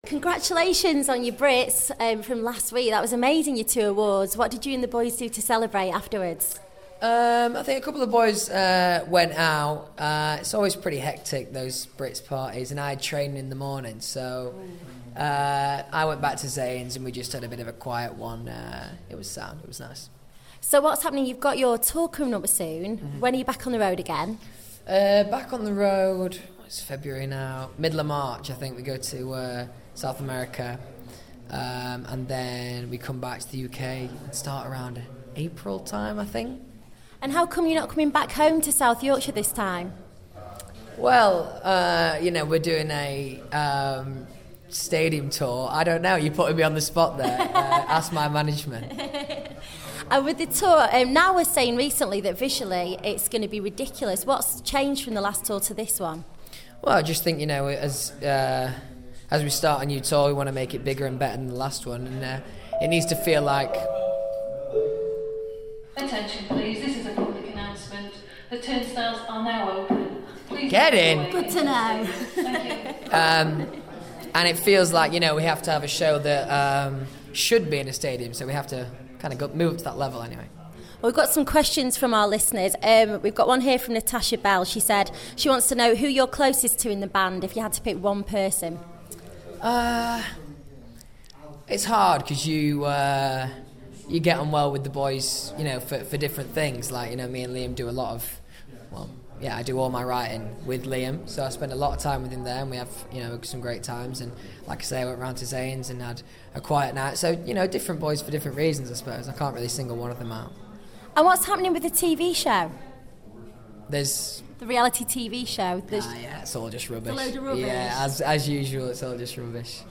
Louis speaks to our reporter before his footballing debut with Doncaster Rovers